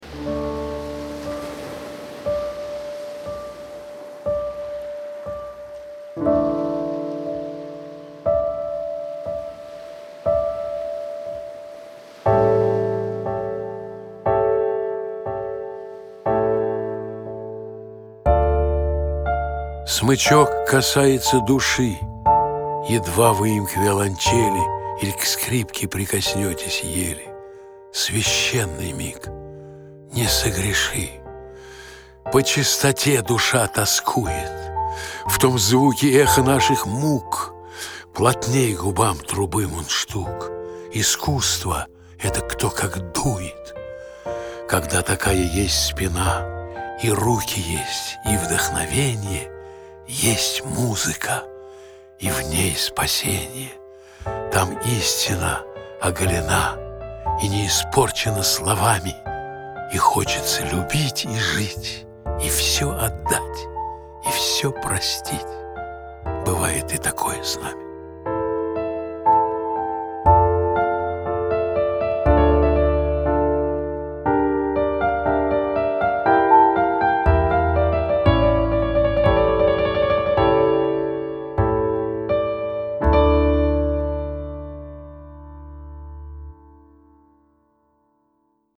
2. «Валентин Гафт – Музыка (читает автор)» /
Gaft-Muzyka-chitaet-avtor-stih-club-ru.mp3